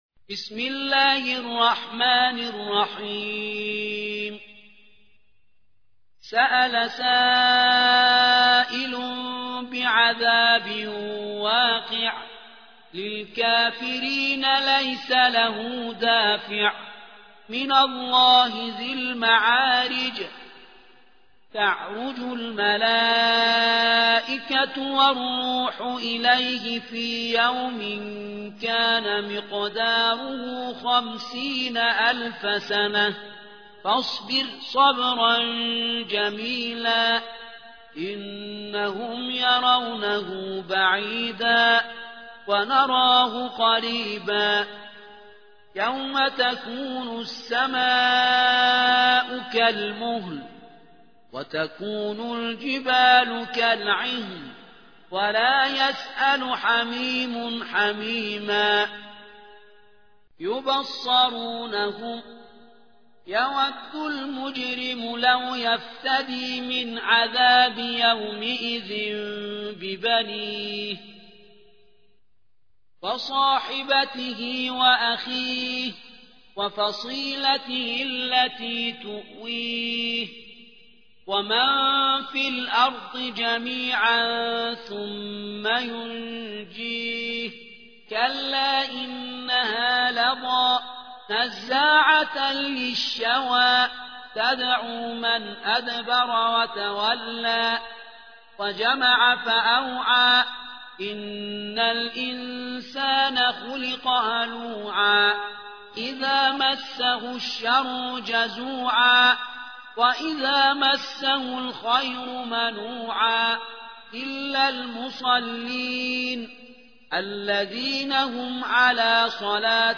70. سورة المعارج / القارئ